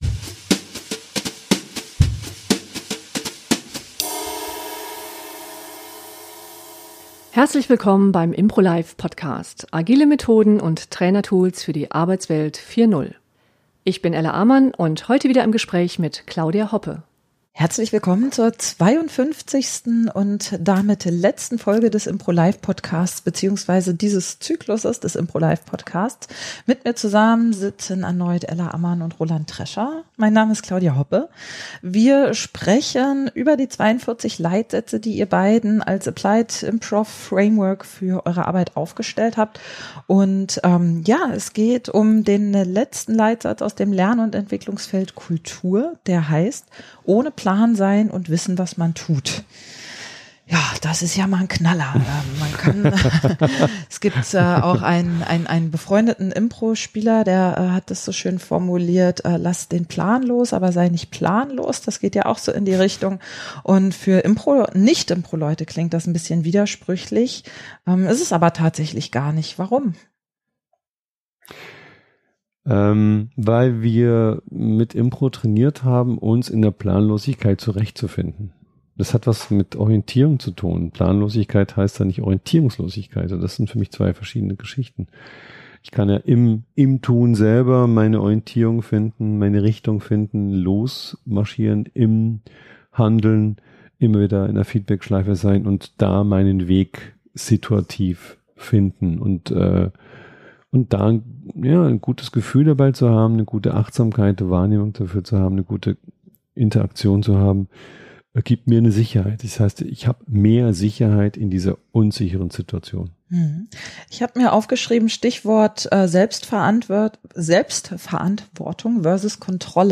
**Im Gespräch https